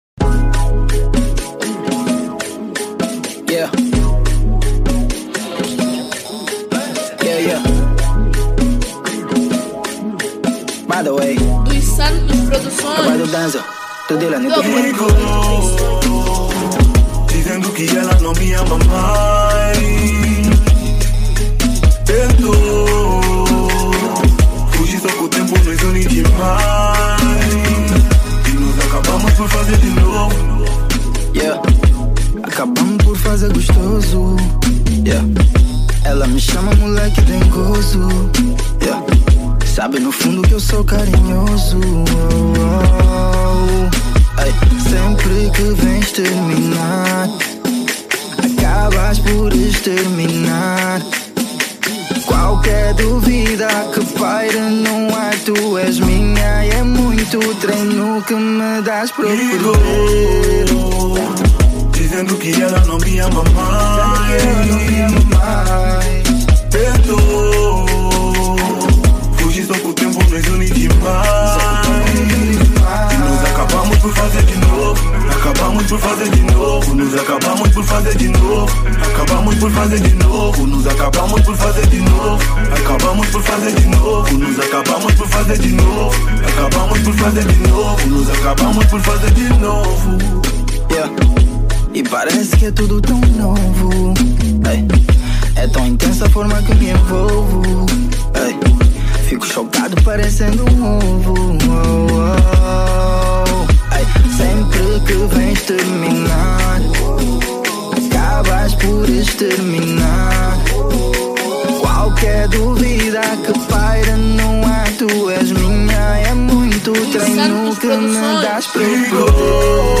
Categoria   Trap Funk